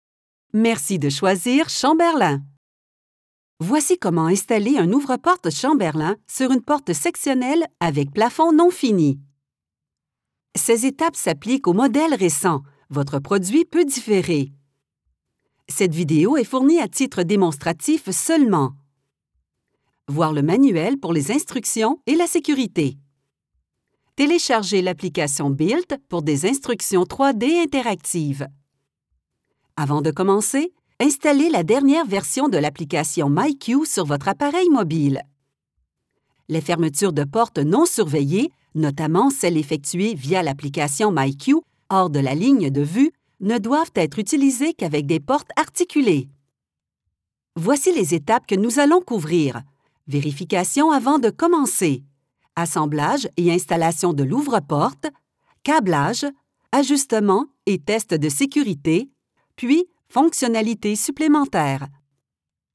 Accessible, Reliable, Corporate
Explainer